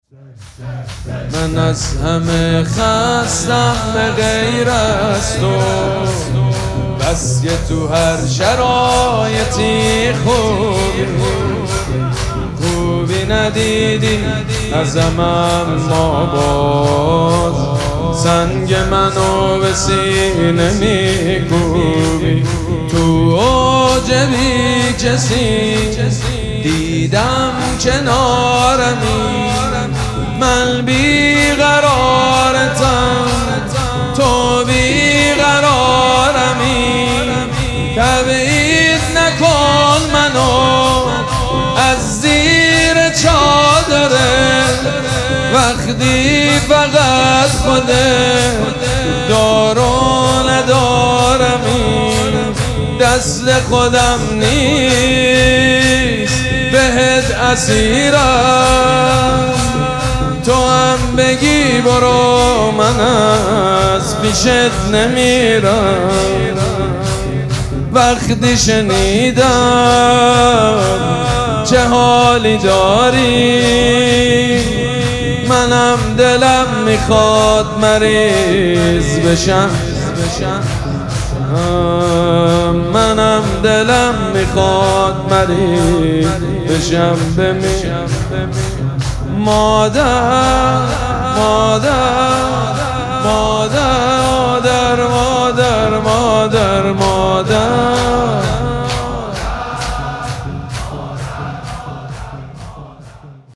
شب پنجم مراسم عزاداری دهه دوم فاطمیه ۱۴۴۶
شور
حاج سید مجید بنی فاطمه